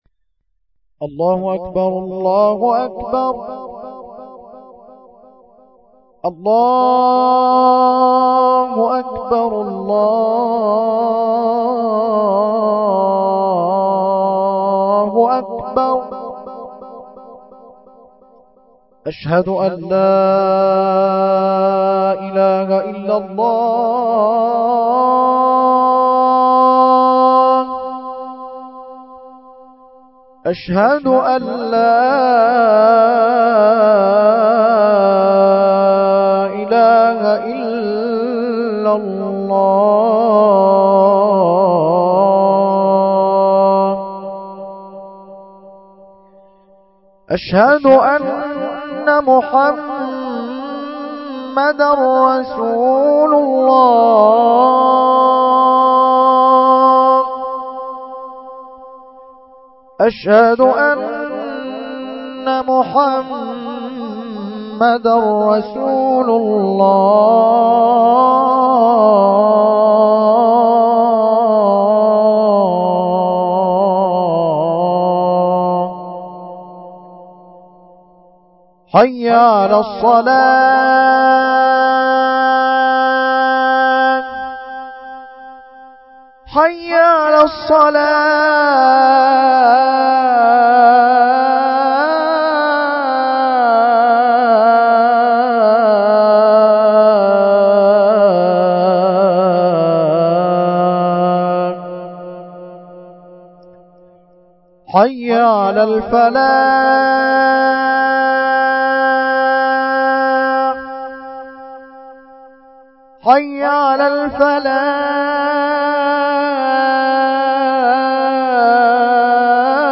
Adhan 20.mp3